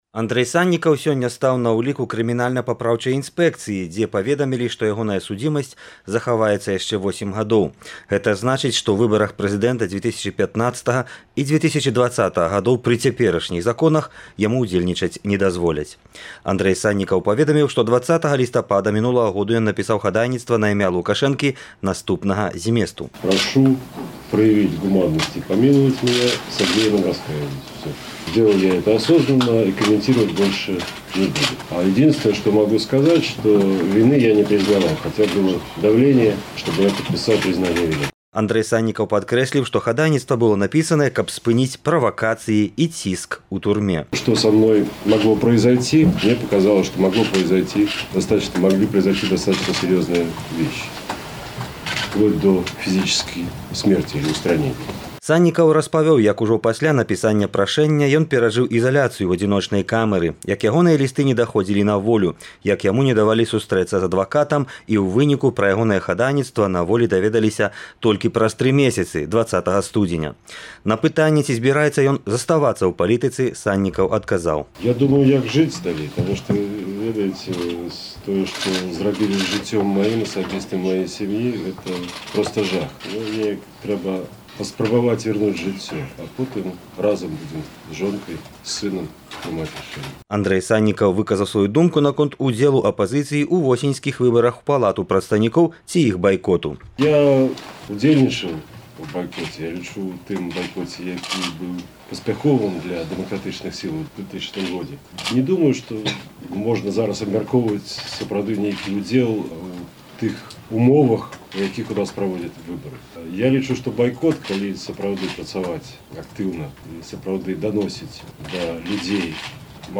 «Свабода» вяла жывы рэпартаж з гэтай падзеі.